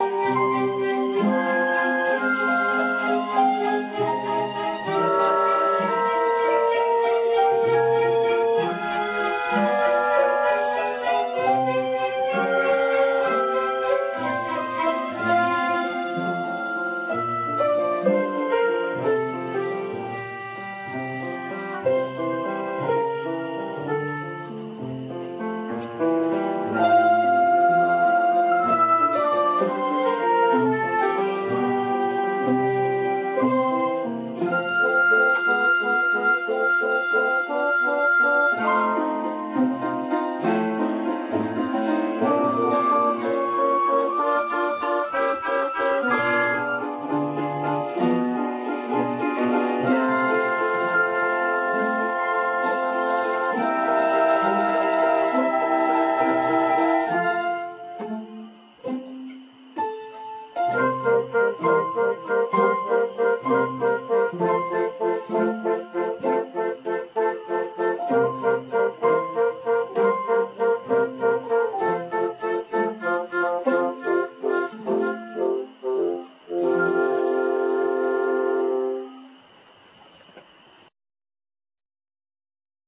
Young-Symphony-Orchestra Wil, St. Gallen/Switzerland
ονειρική ατμόσφαιρα ,
για να ακολουθήσει το τρίτο μέρος παιγμένο με κέφι,
χειροκροτήματα του κοινού!
Video απο το Κονσέρτο